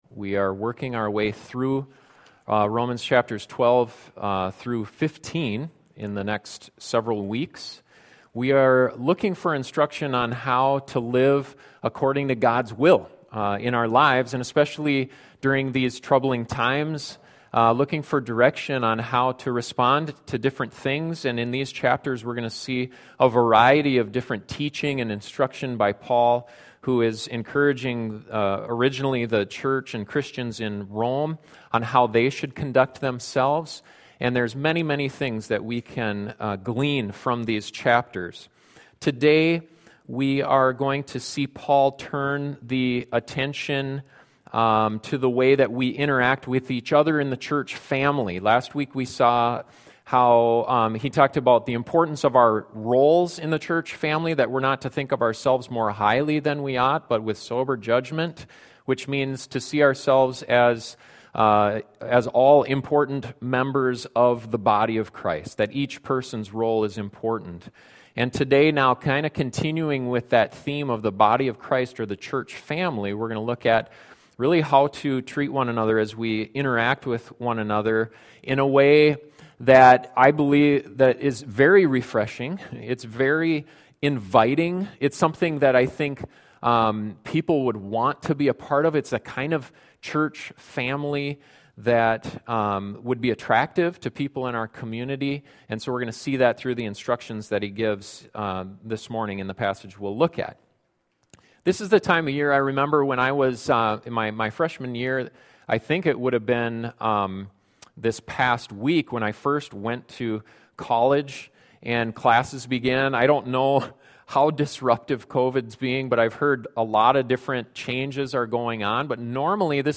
What kind of church family do you and people in your community want to be part of? This sermon looks at several instructions Paul gives for us to be a church that people will be excited about.